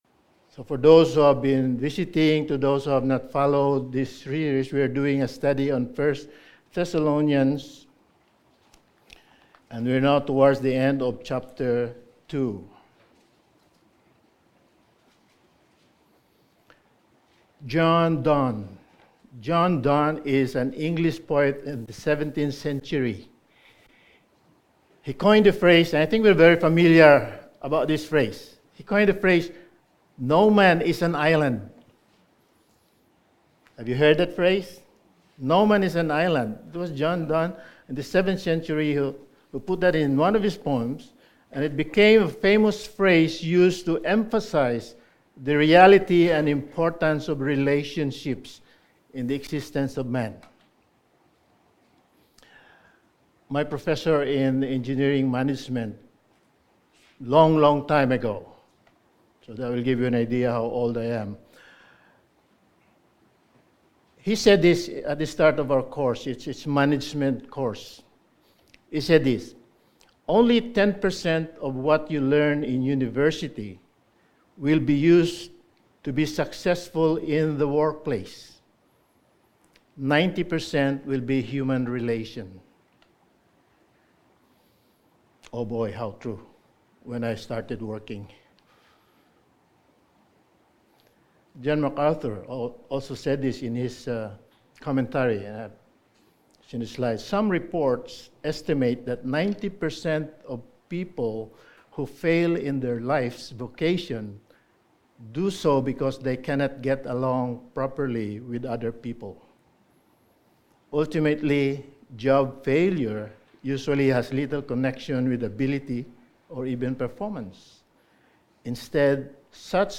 Sermon
Service Type: Sunday Morning Sermon